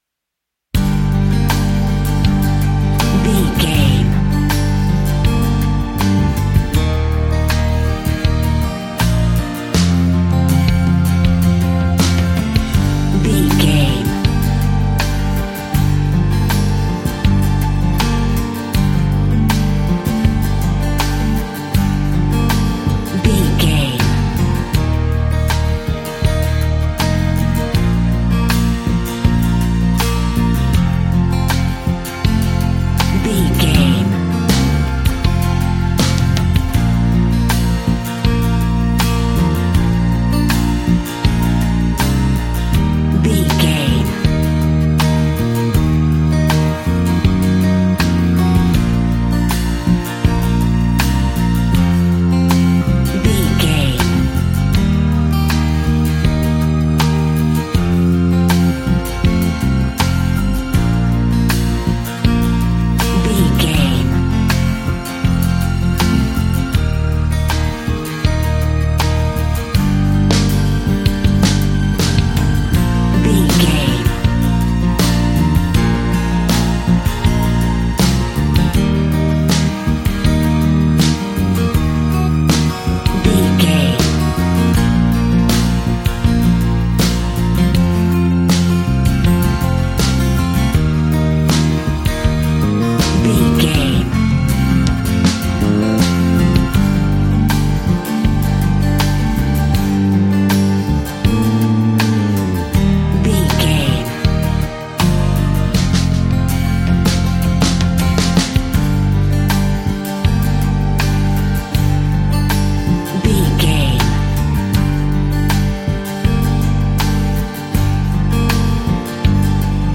Ionian/Major
B♭
sweet
happy
acoustic guitar
bass guitar
drums